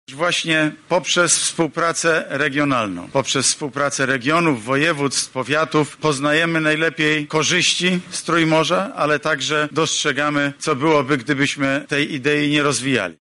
W trakcie ceremonii otwarcia kongresu, premier Mateusz Morawiecki zwracał uwagę na rolę samorządów, jako najbardziej kompetentnych do praktycznego wymiaru Inicjatywy.